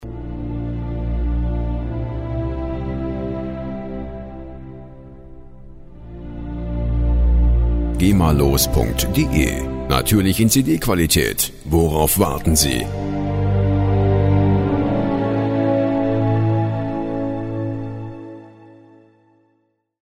Orchestrale Streichinstrumente